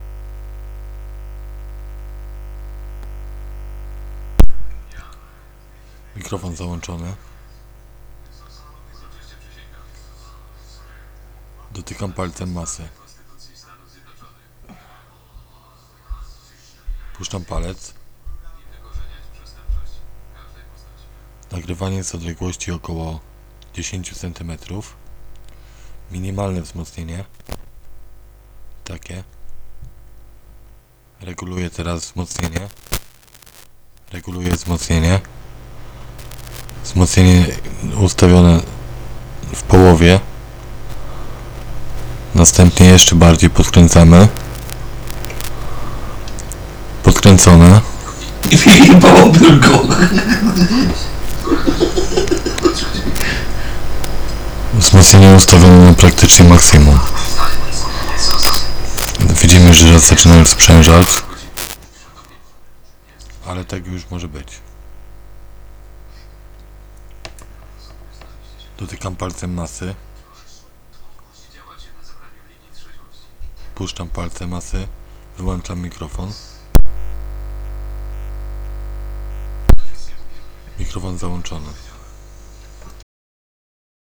Z moją kartą muzyczną UMC22 Behringera na 1/3 wartości wzmocnienia zbiera nawet ciche dźwięki z otoczenia.
Dodam jeszcze że plik audio nagrywałem kiedy układ zasilany był z baterii.
TEST – brzmienie przedwzmacniacza mikrofonowego z zasilaniem bateryjnym
proba mikrofonu.wav